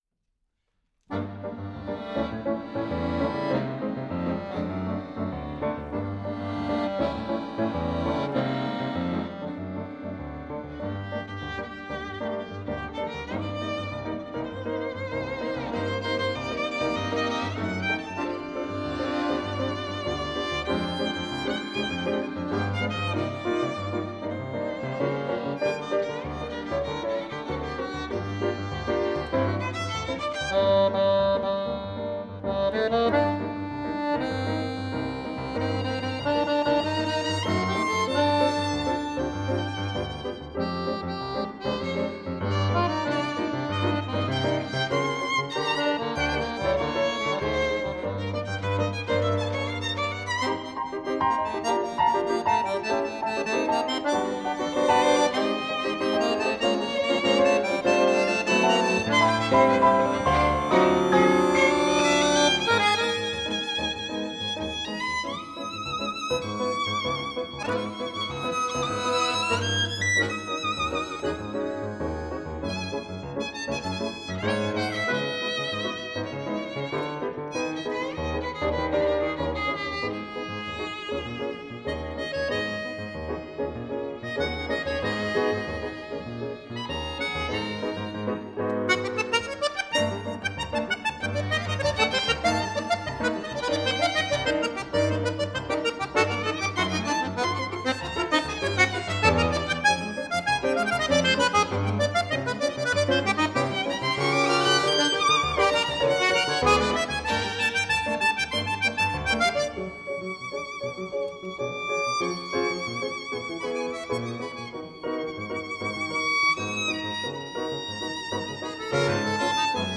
обработка